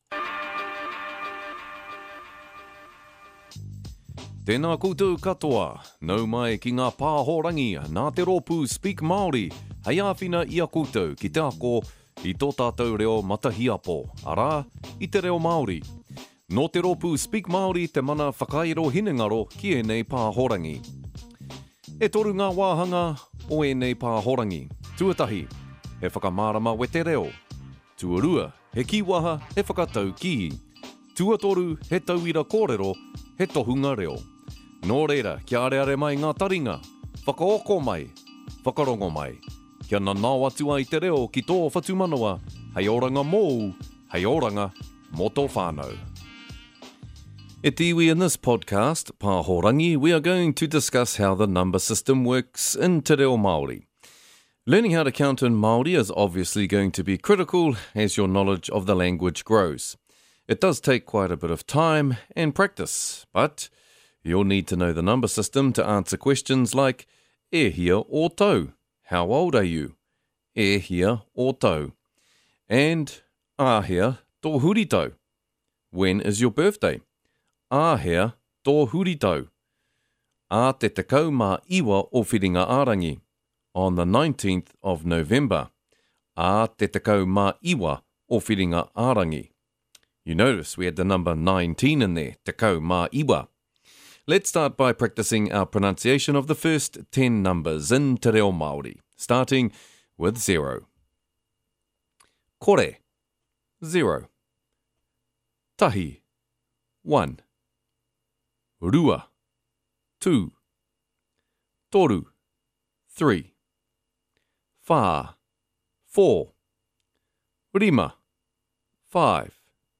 In the final section, you will be exposed to examples of tohunga reo, or expert language models.
Beginners podcasts are bi-lingual, Advanced podcasts are in te reo Māori only.